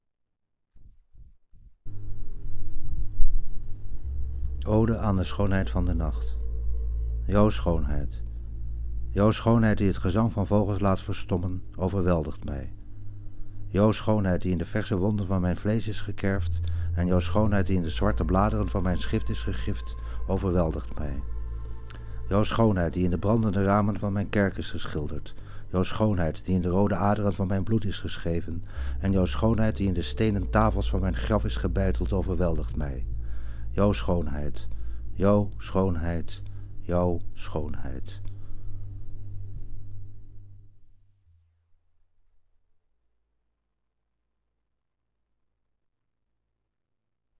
Spoken Word Poetry by yours truly